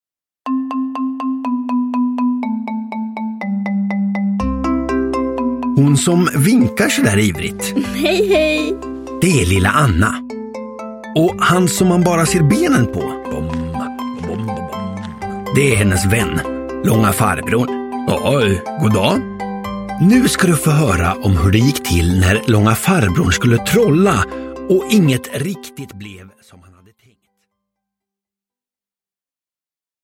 Lilla Anna och trollerihatten – Ljudbok – Laddas ner